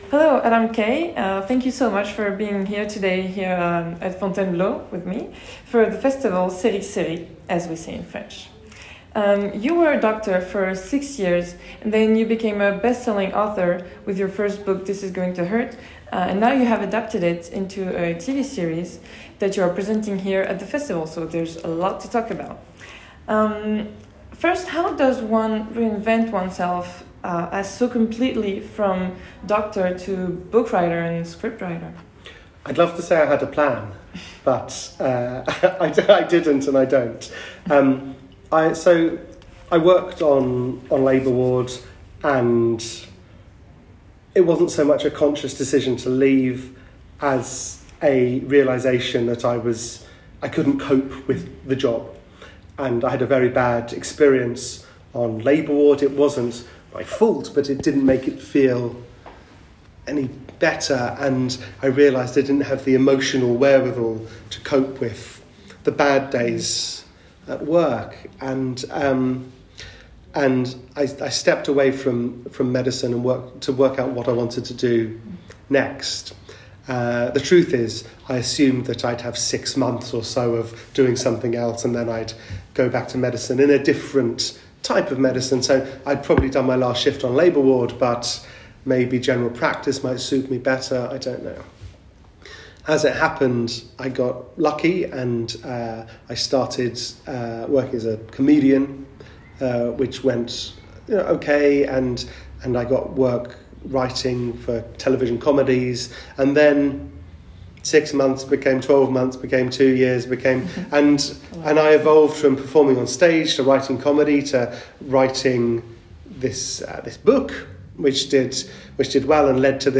Adam Kay nous raconte son expérience de médecin devenu auteur, comédien et humoriste à l'occasion du festival Série Series, les rencontres de Fontainebleau, il répondra lui aussi à cette question qui tue: "peut-on rire de tout ?", ça va faire mal.